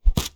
Close Combat Attack Sound 26.wav